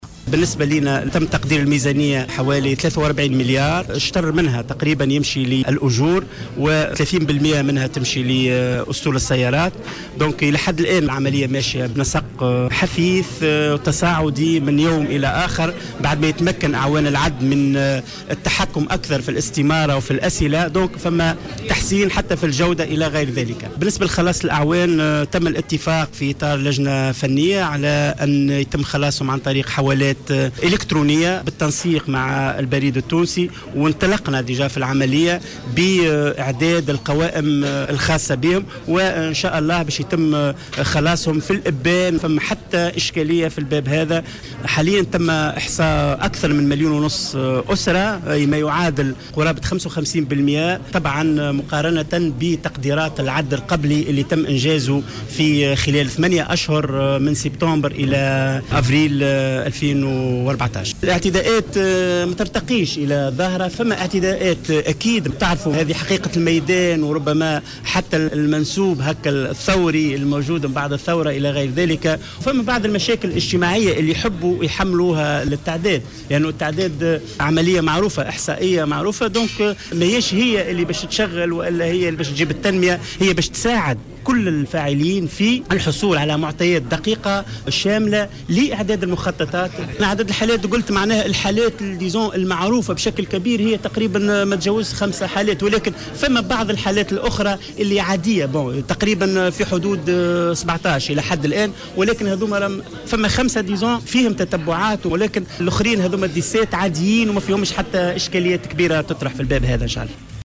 عقد المعهد الوطني للإحصاء ندوة صحفية حول تقدم عملية التعداد العام للسكان و...